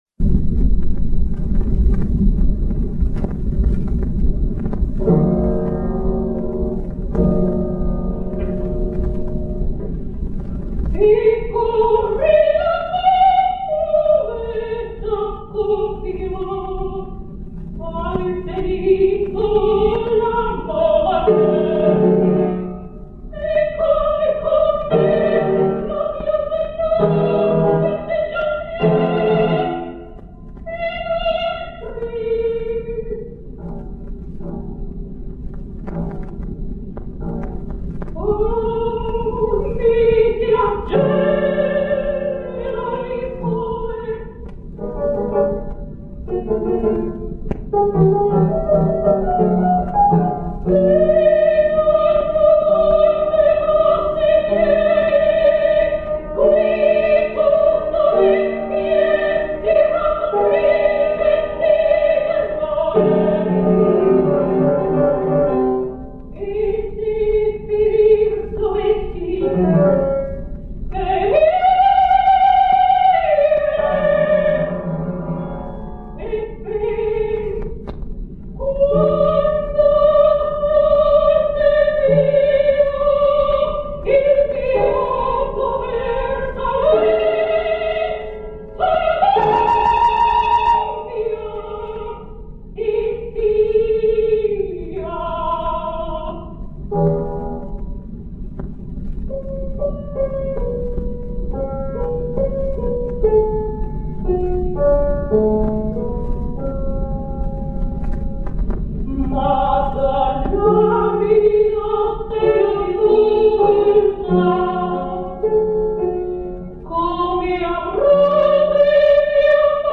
Sopran *1920 Sinnai
(mit Klavier)